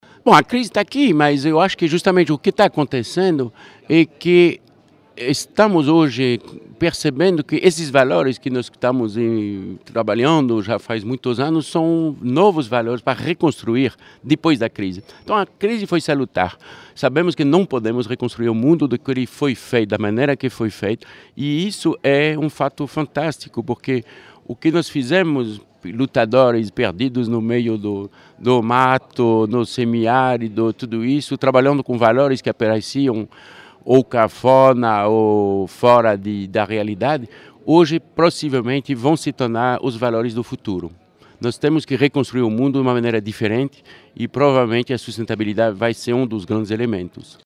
entrevistado na Escola Politécnica Federal de Lausanne.